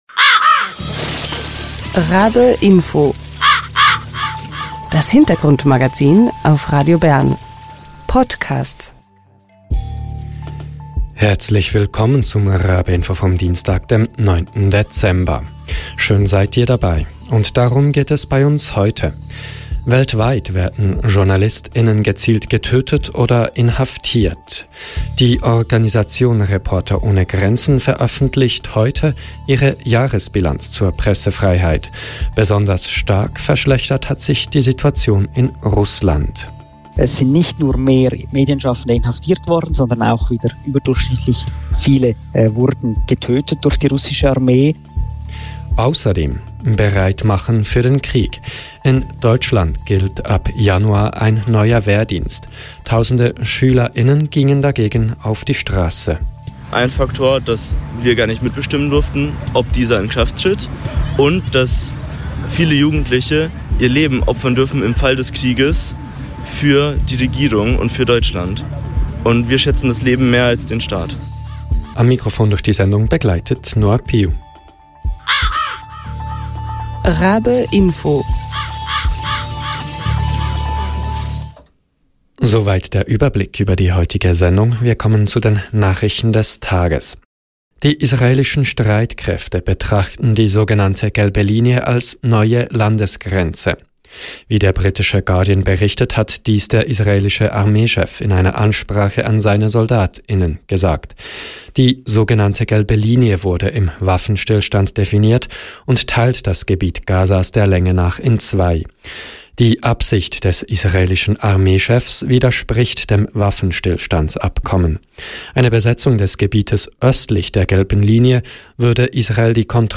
Sie demonstrierten gegen den neuen Wehrdienst. Wir hören eine Reportage von unseren Kolleg*innen von Radio Z in Nürnberg.